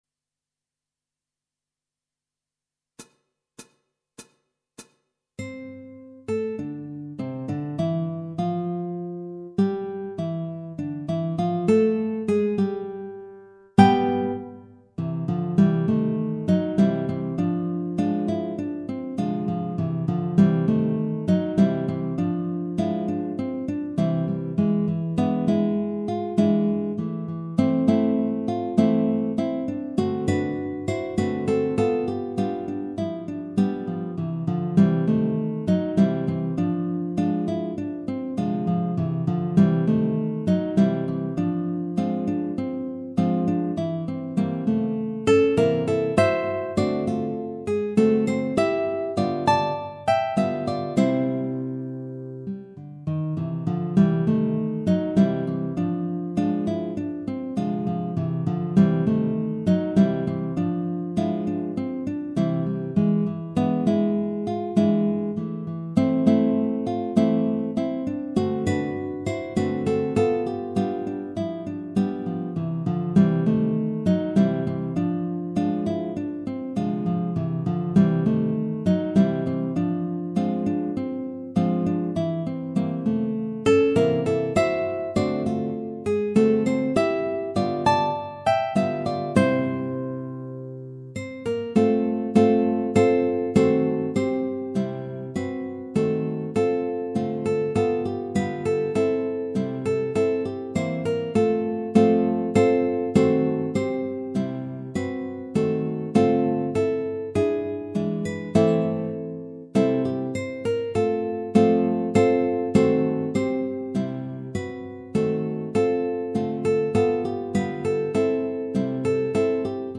Guitar Quartet
minus Guitar 4